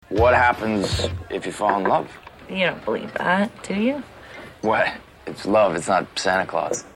기왕 시작했으니 <500일의 섬머> 에서 잘 들리지 않는 예를 하나 더 뽑아봤어요.
이 문장에서는 you don’t believe that, do you?에서 don’t 나 that같은 기능어를 입안에서 우물우물하고 정확하게 발음하지 않는 경향 때문에죠.